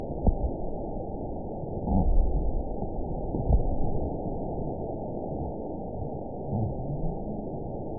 event 920552 date 03/30/24 time 01:59:39 GMT (1 year, 1 month ago) score 9.18 location TSS-AB04 detected by nrw target species NRW annotations +NRW Spectrogram: Frequency (kHz) vs. Time (s) audio not available .wav